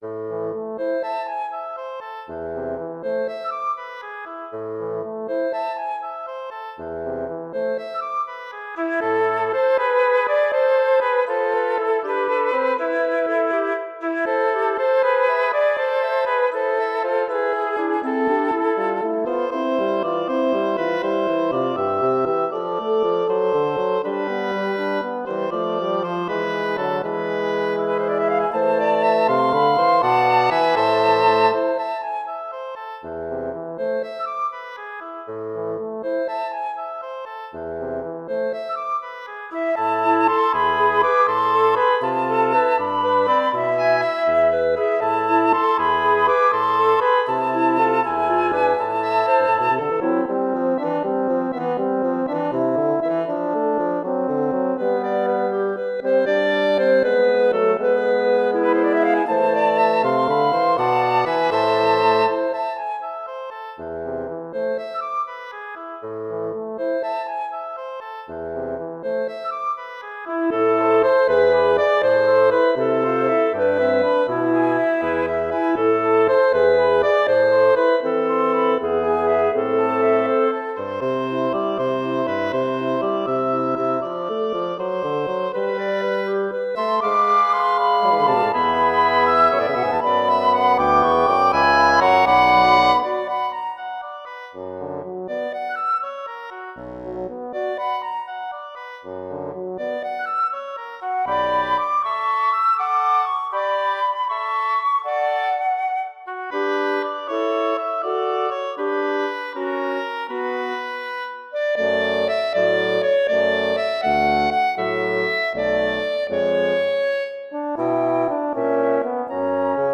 Here's my first woodwind quintet based on a Basque melody.